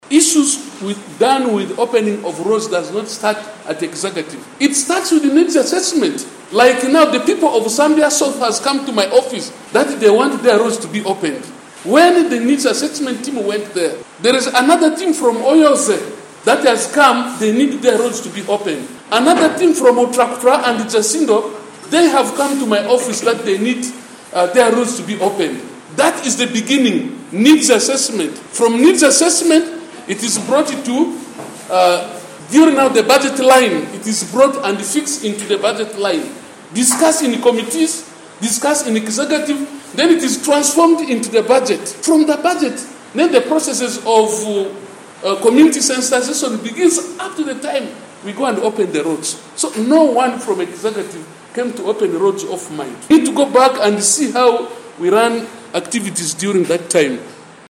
During a council sitting on Thursday, September 28, 2023, at the division headquarters, Mayor Muzaid Khemis announced the proposal to open new roads in the area.
Mayor Muzaid Khemis, in his address, highlighted the urgency of the situation, emphasizing the high demand for road openings.